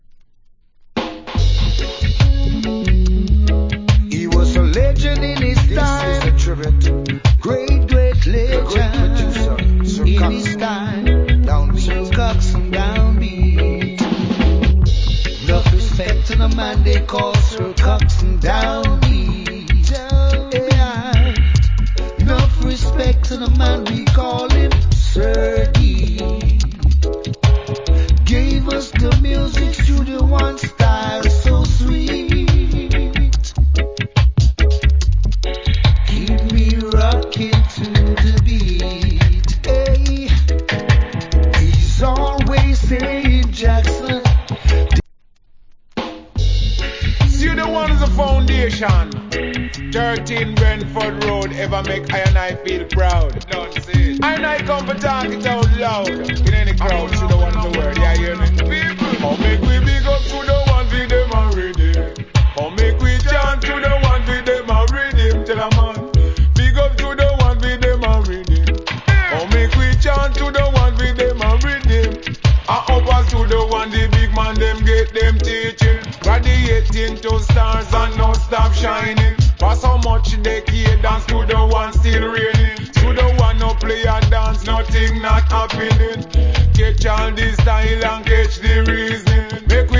コメント Wicked Reggae Vocal. / Nice Combination Vocal.